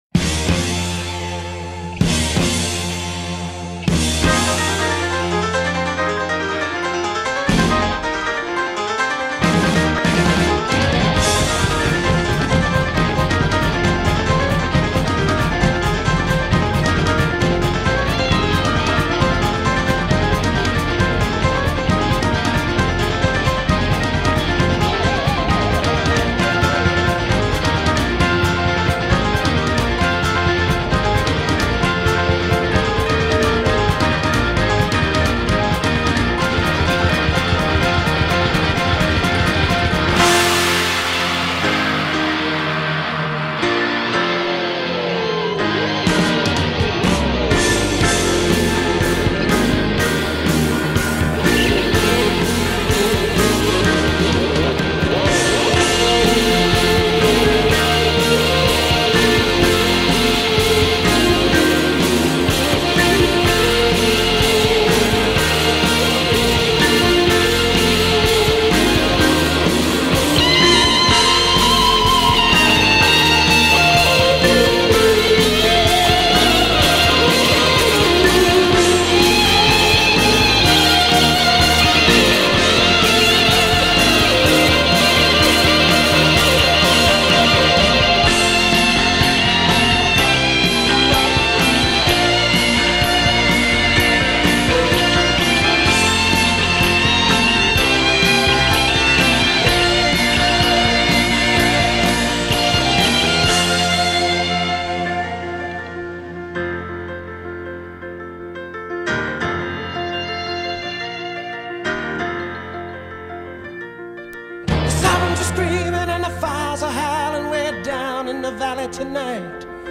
Rock, Pop Rock